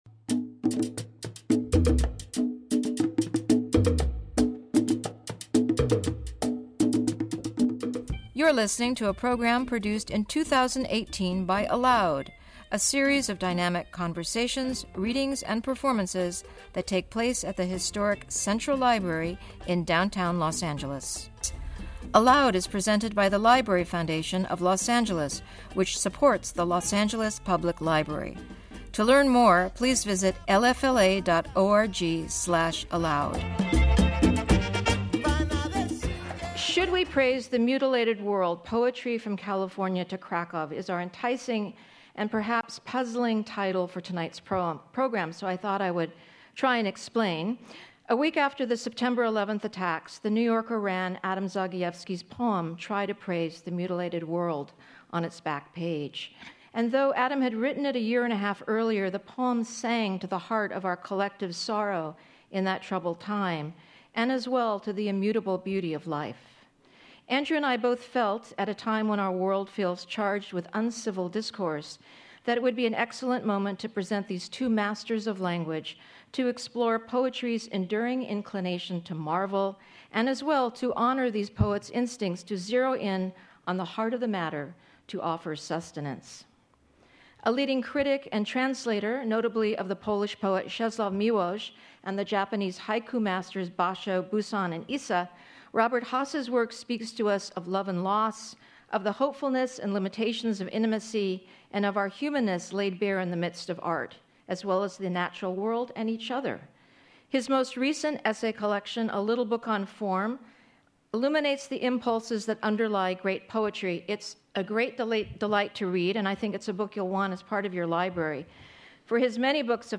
Two of the world’s greatest living poets come together for a rare Los Angeles reading and conversation. The work of Robert Hass, former U.S. Poet Laureate and long-time translator of Nobel Laureate Czesław Miłosz, speaks to us of love and loss, of the hopefulness and the limitations of intimacy, of our humanness laid bare in the midst of art, the natural world, and each other.
Robert Hass and Adam Zagajewski Reading and Conversation